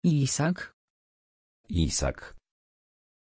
Pronuncia di Iisak
pronunciation-iisak-pl.mp3